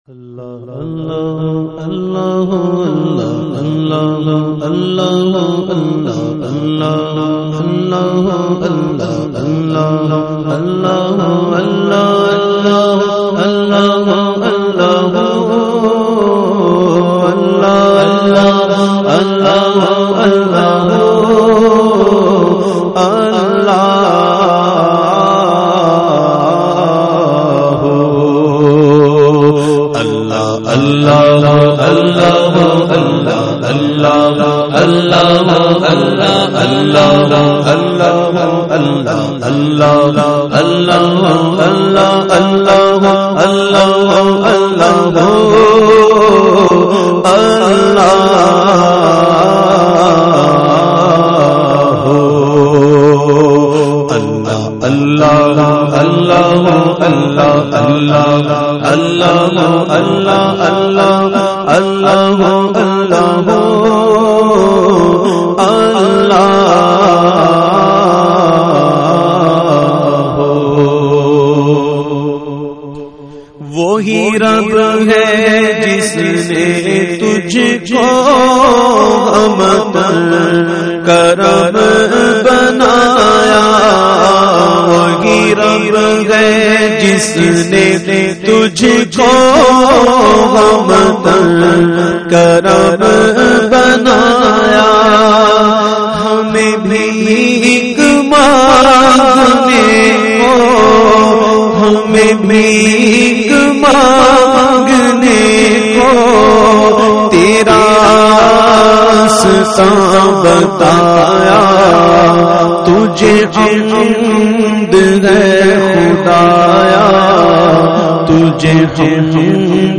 The Naat Sharif Ahle Sirat recited by famous Naat Khawan of Pakistan Owaise Qadri.